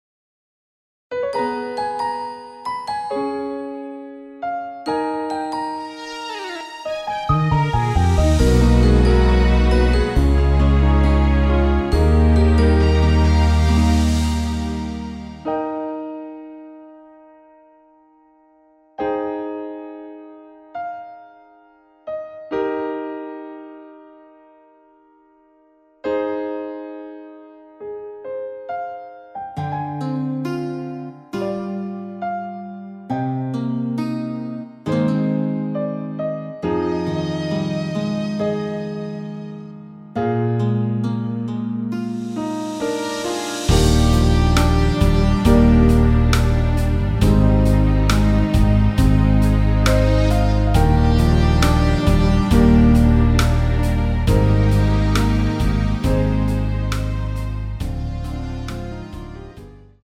Eb
◈ 곡명 옆 (-1)은 반음 내림, (+1)은 반음 올림 입니다.
앞부분30초, 뒷부분30초씩 편집해서 올려 드리고 있습니다.
중간에 음이 끈어지고 다시 나오는 이유는